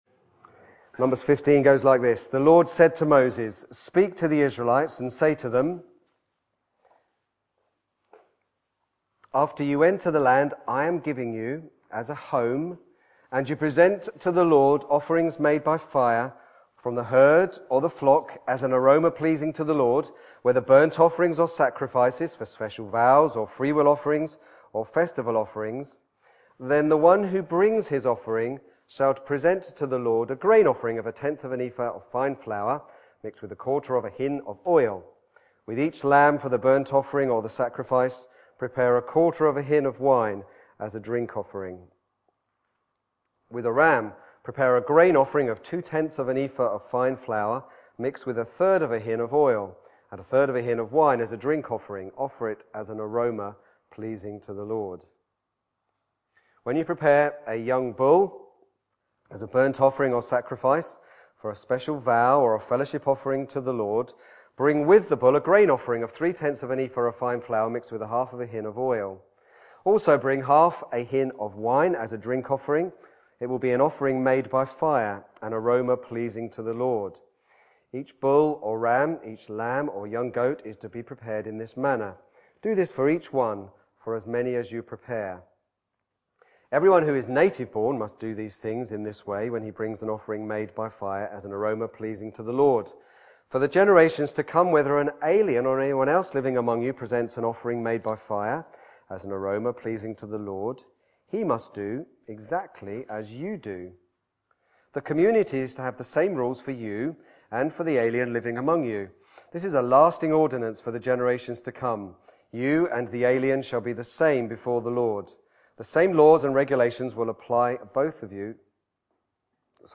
Back to Sermons Grace we do not deserve